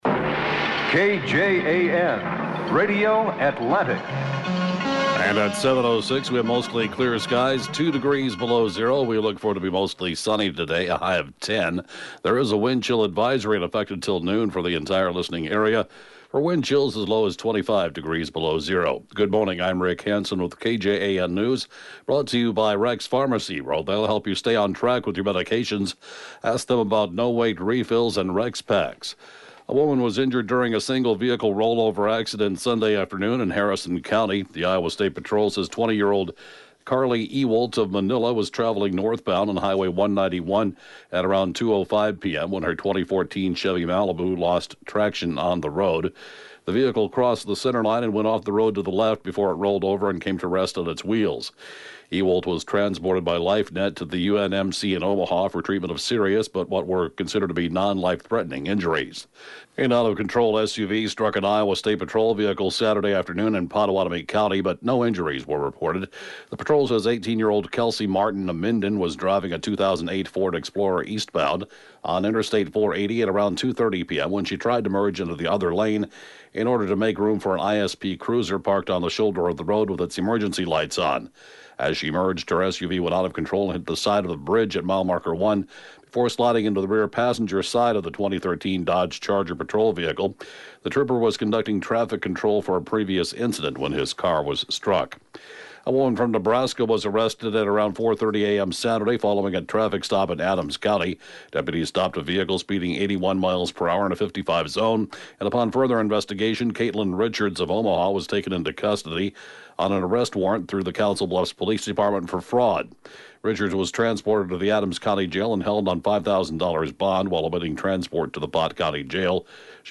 (Podcast) KJAN Morning News & Funeral report, 3/27/2019